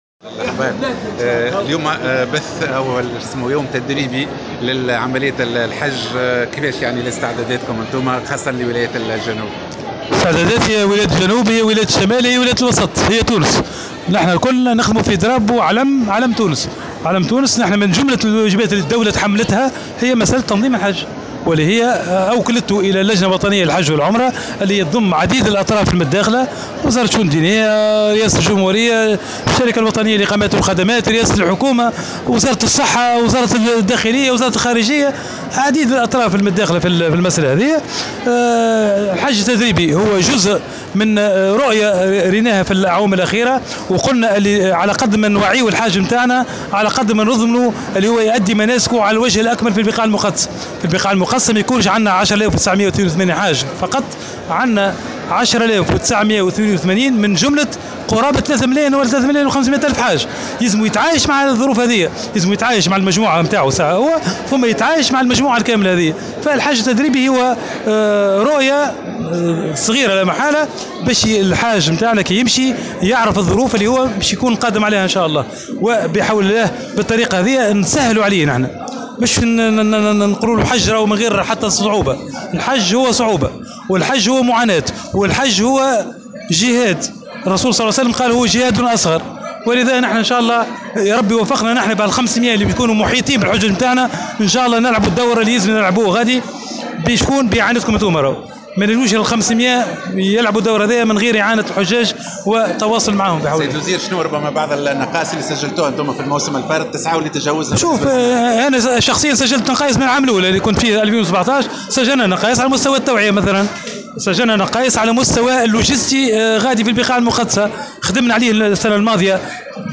وأوضح في تصريح لمراسل "الجوهرة أف أم" على هامش تظاهرة الحجّ التدريبي بصفاقس، أن هذه التجربة ستتم بالتعاون مع جمعية "رعاية ضيوف الرحمان" وتشمل 90 حاجا متطوّعا من صغار السنّ نسبيا (بين 30 و50 عاما) ليشكلون نواة إضافية إلى جانب بعثة الحجاج الرسمية للقيام بدور الإحاطة والتوعية، وفق تعبيره. وشدّد على أهمّية التوعية، مشيرا إلى أهمية مجهود الحجّاج للمحافظة على نظافة المخيمات بالبقاع المقدسة.